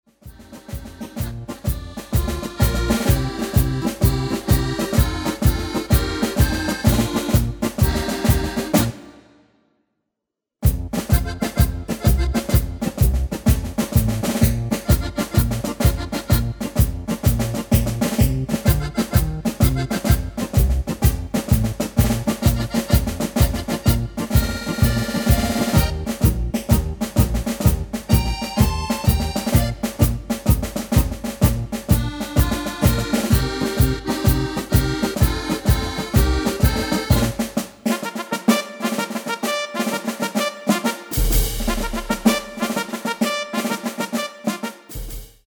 Demo/Koop midifile
Genre: Nederlandse Oldies
Toonsoort: A#
- Vocal harmony tracks
Demo's zijn eigen opnames van onze digitale arrangementen.